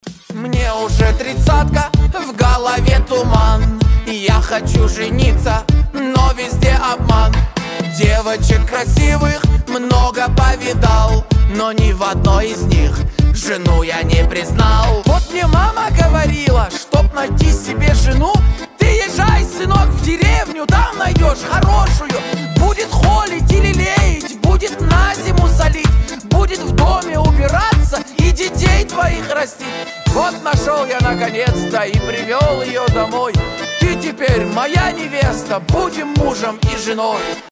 • Качество: 320, Stereo
веселые
заводные
аккордеон